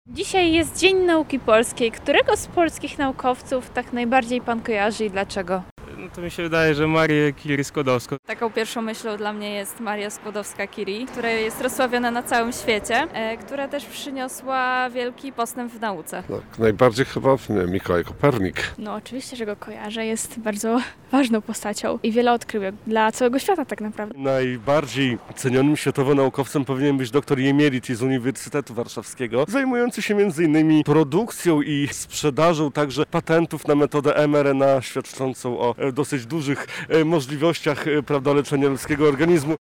rozmowy z mieszkańcami Lublina